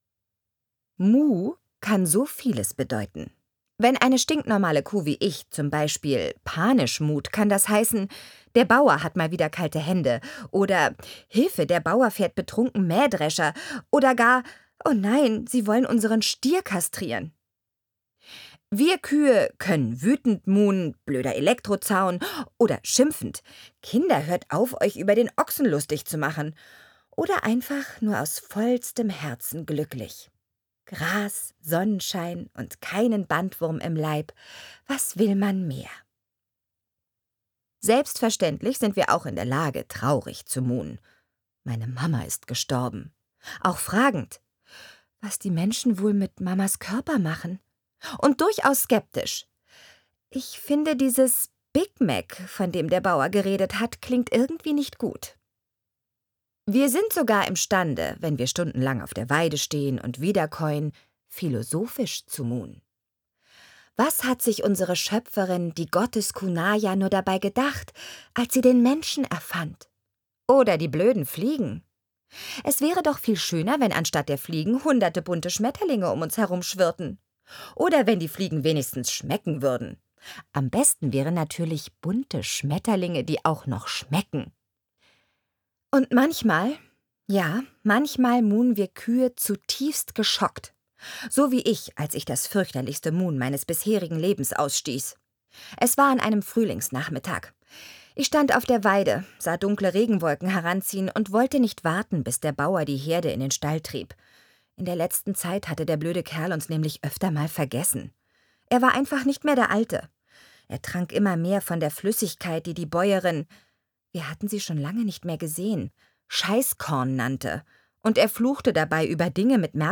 Nana Spier (Sprecher)
Nana Spiers vielseitige, so humorvolle wie sinnliche Stimme macht sie zu einer überaus beliebten Hörbuchsprecherin.
Schlagworte Hörbuch; Literaturlesung • Kuh / Kühe; Romane/Erzählungen • Kuh; Romane/Erzählungen • Rind; Romane/Erzählungen • Rind; Roman/Erzählung